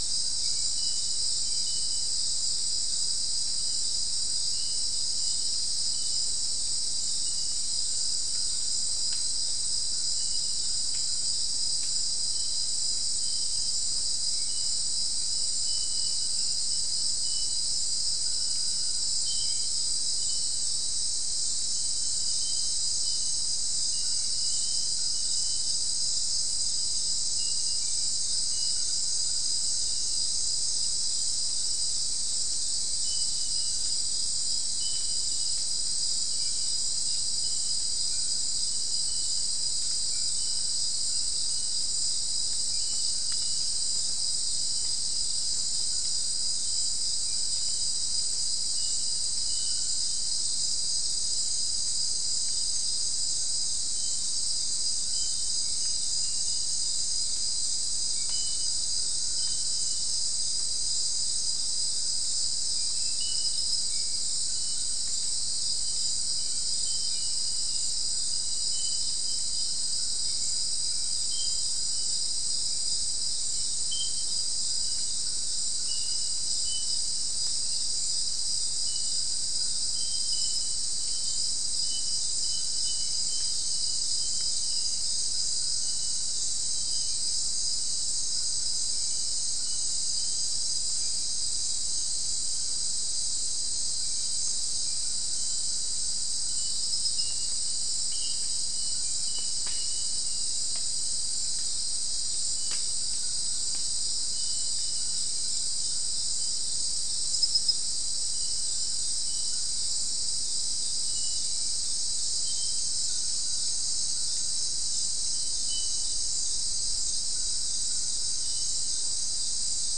Soundscape
Recorder: SM3